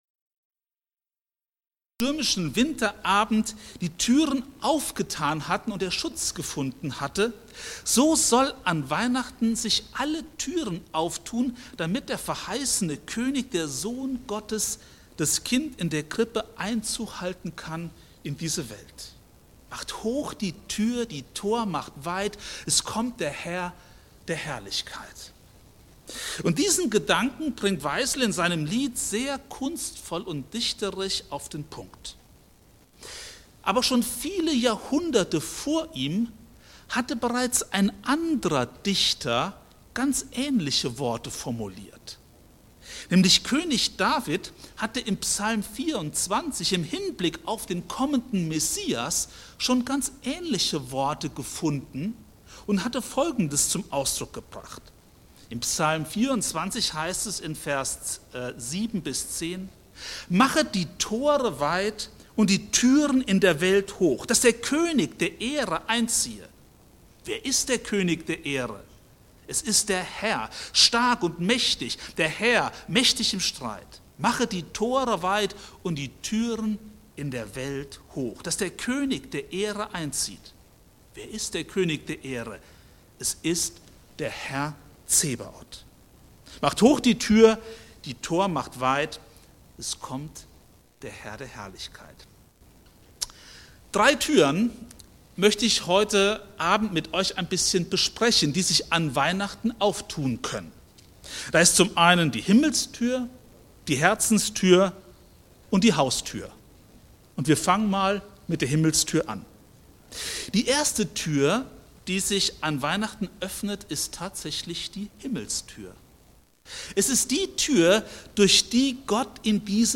Ausgehend von Psalm 24 und der Weihnachtsgeschichte zeigt diese Predigt: Weihnachten ist das Fest der offenen Türen – der geöffneten Himmelstür, der Herzenstür und der Tür zum Nächsten. Gott selbst macht den Anfang, indem er in Jesus Christus Mensch wird und Nähe sucht.